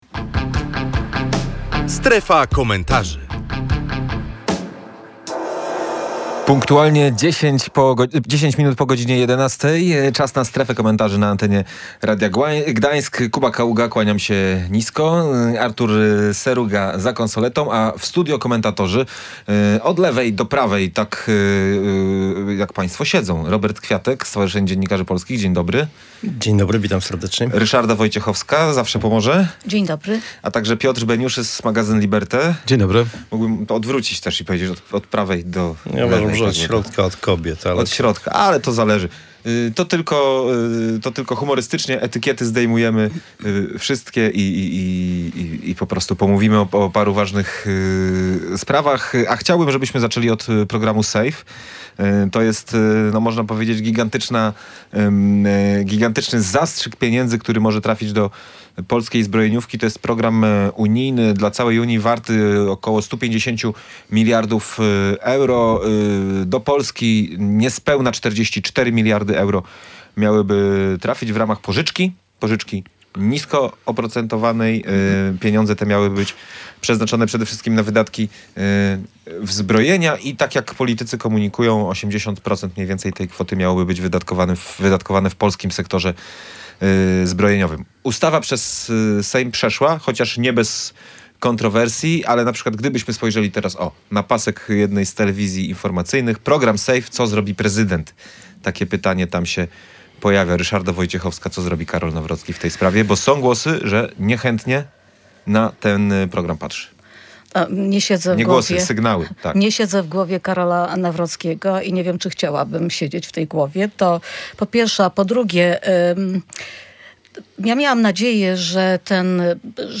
Czy to, co wolno wszystkim, wolno prezydentowi? Między innymi o tym rozmawialiśmy w „Strefie Komentarzy”.